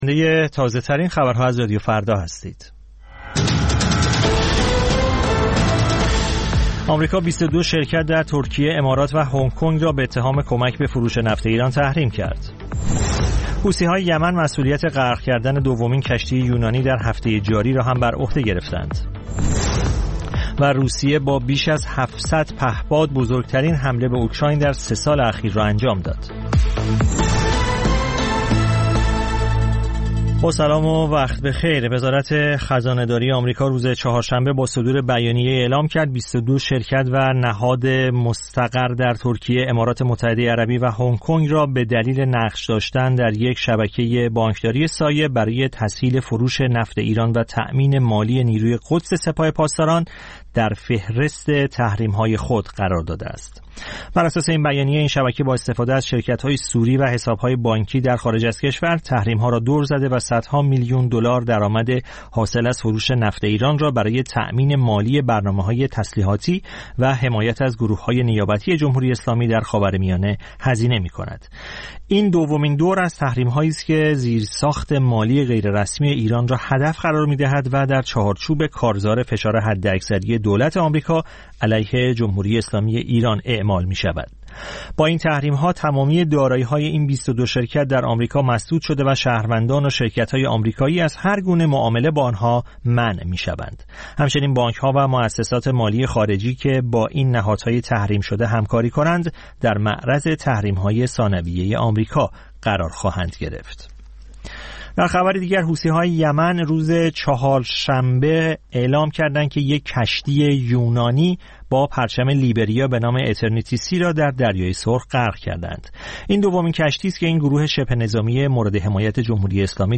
سرخط خبرها ۹:۰۰
پخش زنده - پخش رادیویی